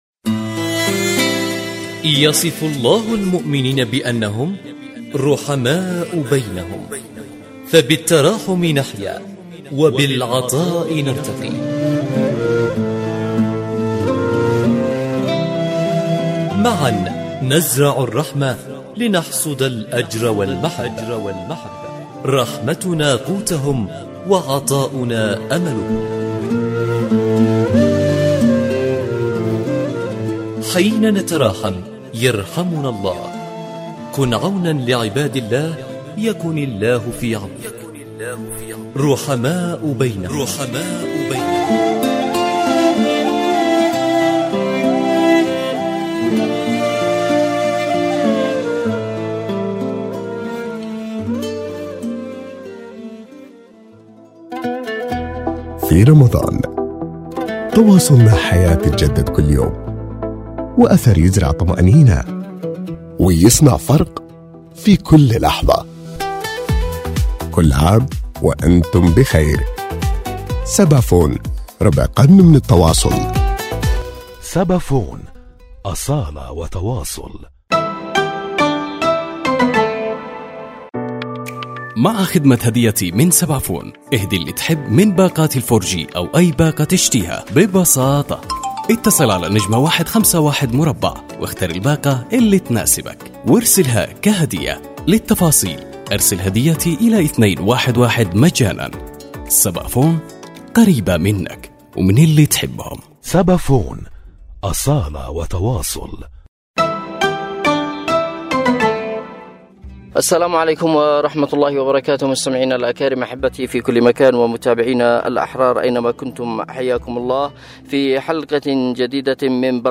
رحماء بينهم، برنامج إذاعي يأخذكم في رحلة إذاعية قصيرة ، نستكشف خلالها العديد من الحالات الإنسانية التي تحتاج الى مد يد العون في شهر الخيرات، ويسلط الضوء على حالات إنسانية. تحتاج الى المساعدة والانفاق.